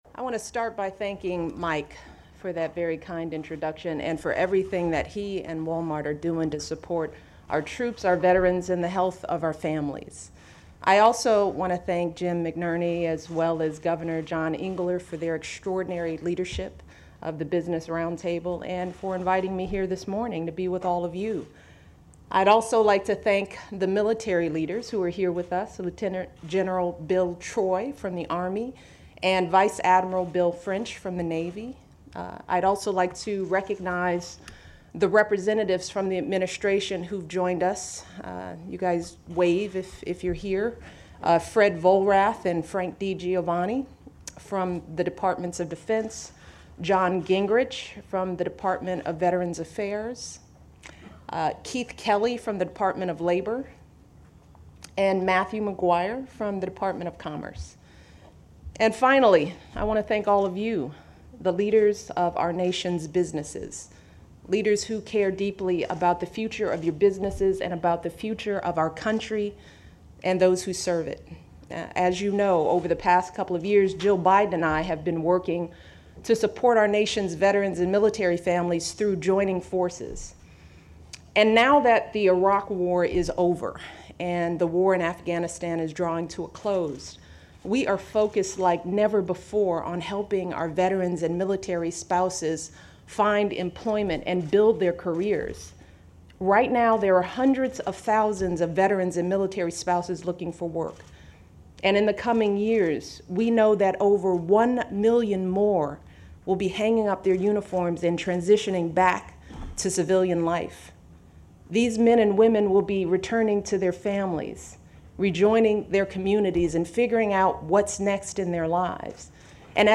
As part of her Joining Forces initiative, U.S. First Lady Michelle Obama delivers remarks to the quarterly meeting of the Business Roundtable